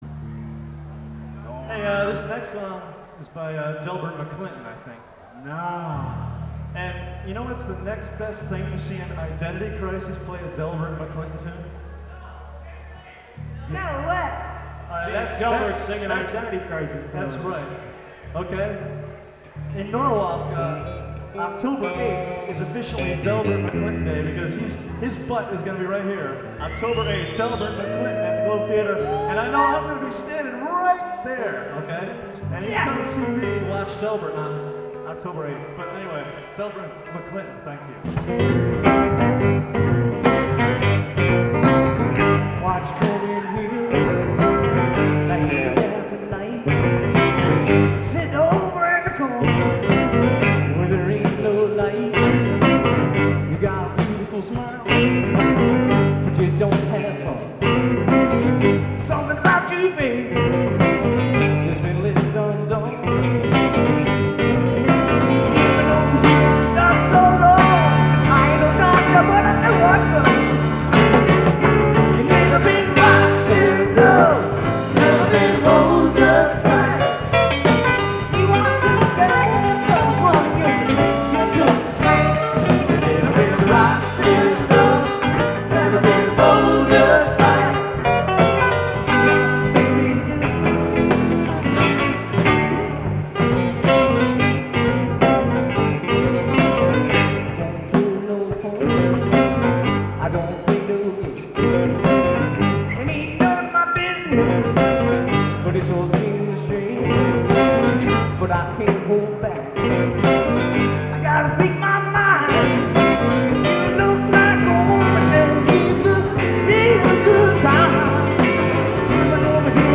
Live Recordings
Norwalk, CT